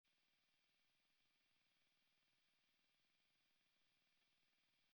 As some others have posted, I have a hiss when using with headphones. It’s always there and the level of the hiss never changes when adjusting amp and IR levels. The hiss is there when my amp is on standby.
The uploaded audio is from the headphone output.